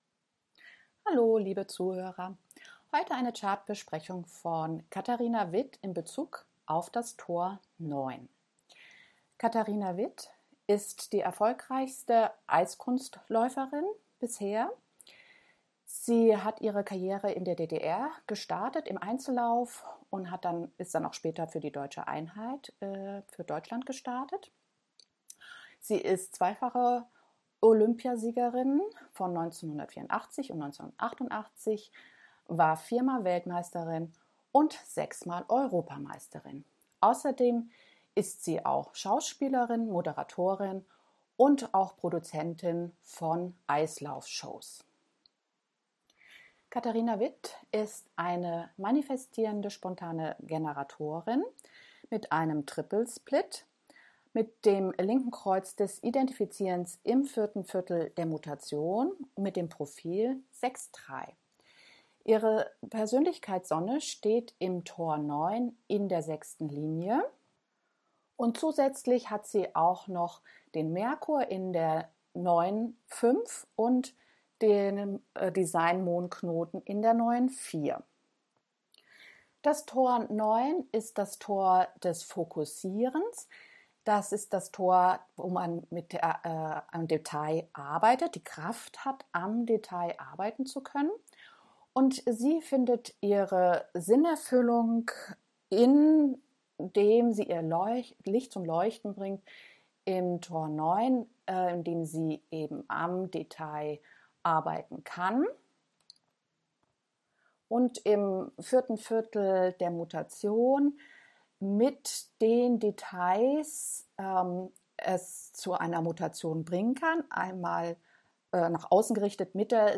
Zu ihrem heutigen Geburtstag eine Wiederholung des Radiobeitrags über Katharina Witt in Bezug auf das Tor 9.